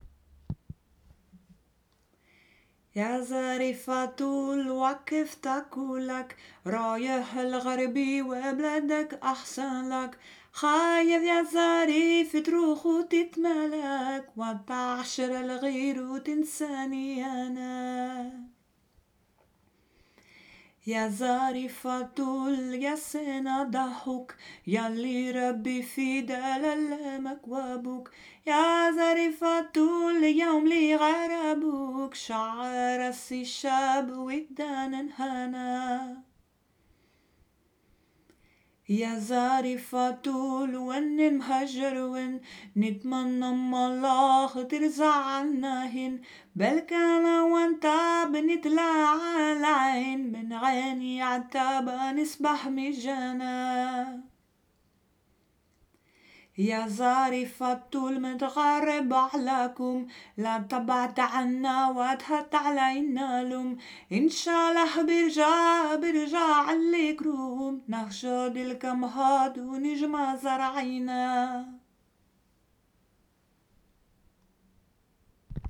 Palestinian folk song
(voice)